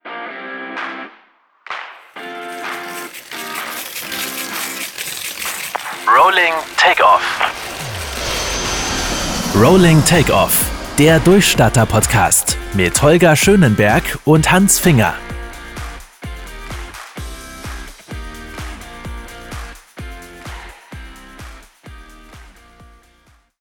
Produktion eines Audio-Podcast-Intros und Outros mit Musik, Texterstellung und zusätzl. instrumentalem Musikbett | Länge jeweils max. 30 Sek.
• Verwendung von Soundeffekten
Intro-Rolling-Takeoff.mp3